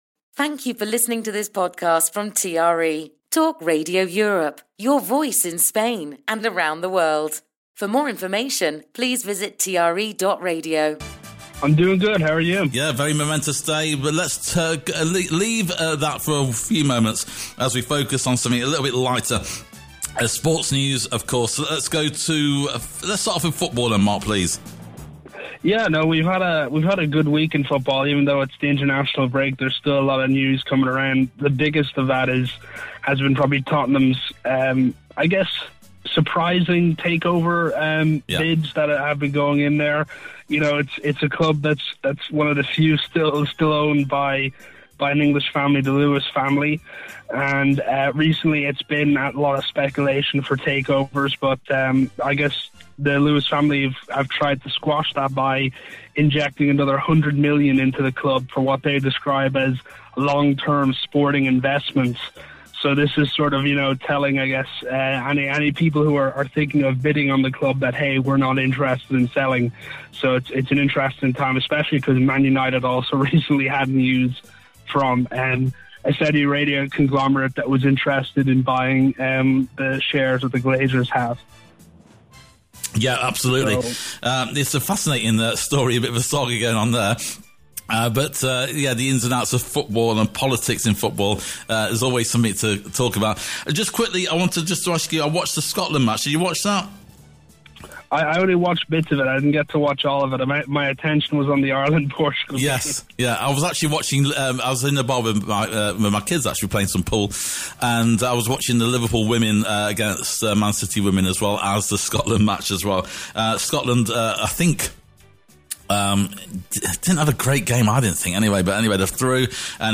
a segment in TRE's flagship breakfast show